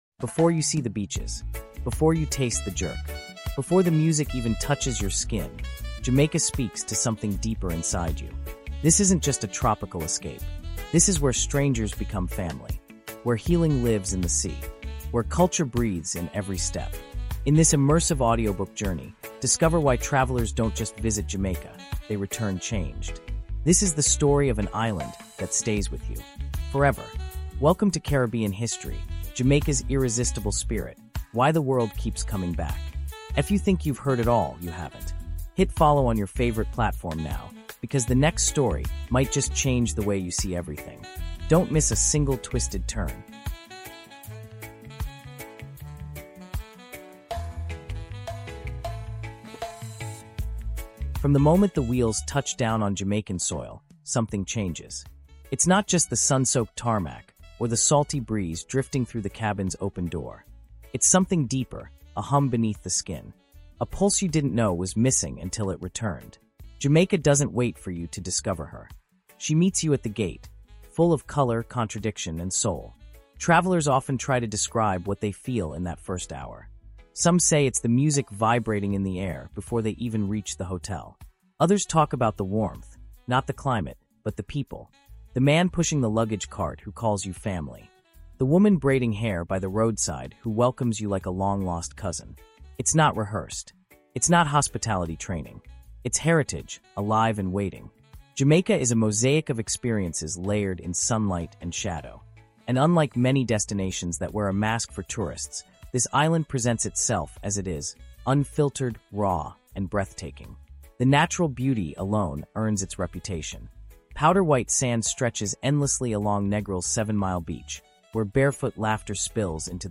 In this immersive Caribbean History audiobook documentary, we uncover the deeper truth behind why the world keeps returning to this island.
Narrated in vivid, cinematic storytelling, this journey captures the very heartbeat of Jamaica — its island vibes, its cultural strength, and the magic that lingers in every memory.